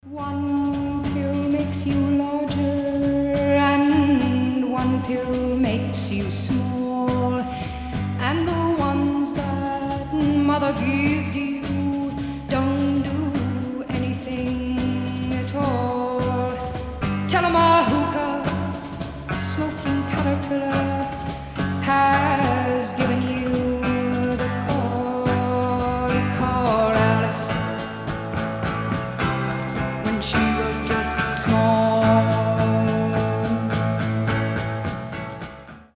психоделический рок
60-е